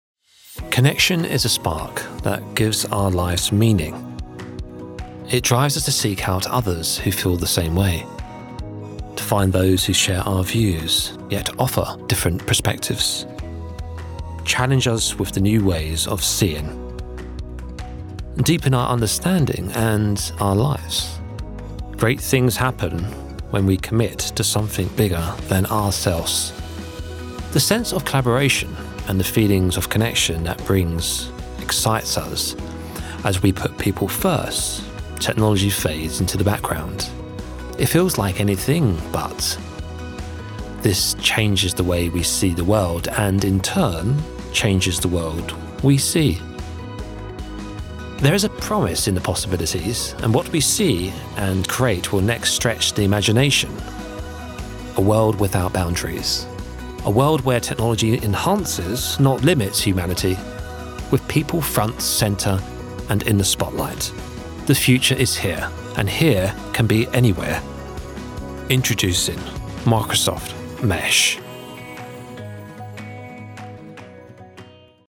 Narration
Mon accent est celui de l'estuaire, mais la plupart me connaissent comme un gars de l'Essex.
Baryton